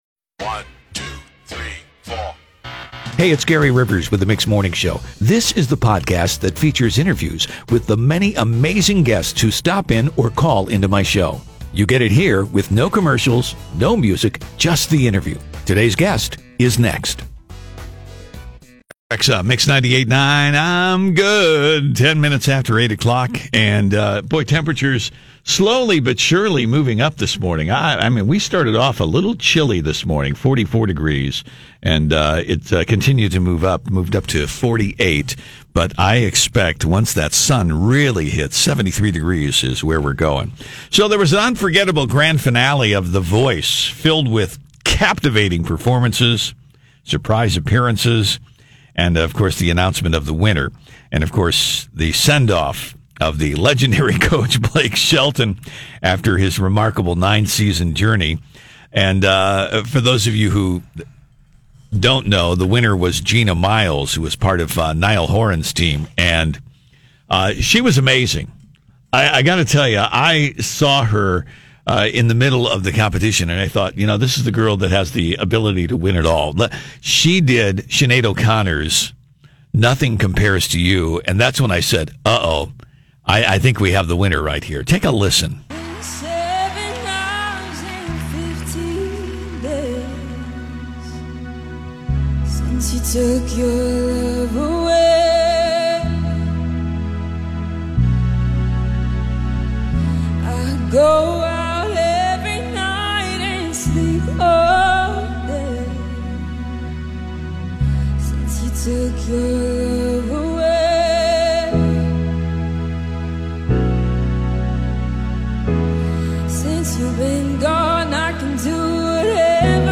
Gina Miles Interview https